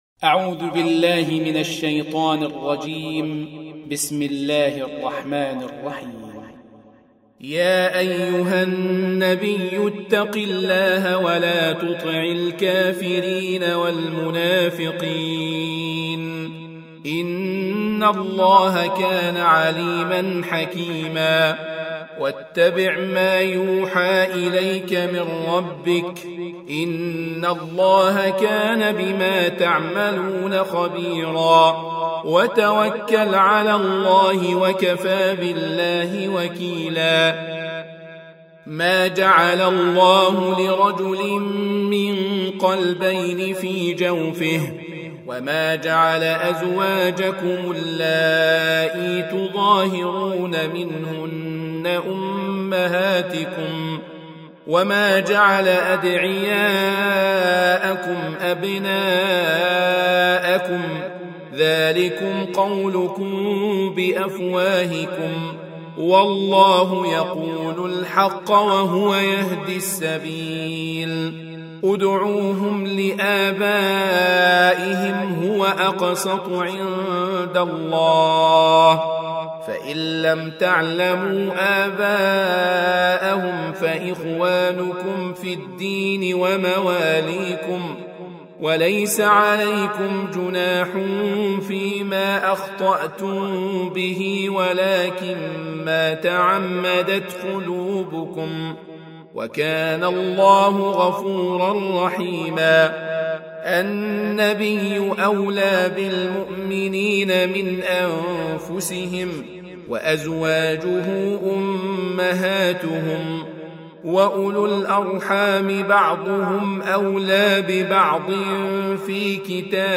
Audio Quran Tarteel Recitation
Surah Sequence تتابع السورة Download Surah حمّل السورة Reciting Murattalah Audio for 33. Surah Al�Ahz�b سورة الأحزاب N.B *Surah Includes Al-Basmalah Reciters Sequents تتابع التلاوات Reciters Repeats تكرار التلاوات